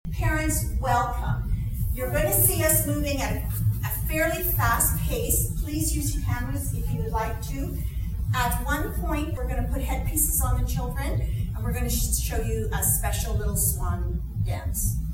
The community hall at the myFM Centre was filled with pretty music, smiling family members and the cutest little ballerinas as the Town of Renfrew’s youth ballet program wrapped up for the season Thursday.
may-16-ballet-welcome.mp3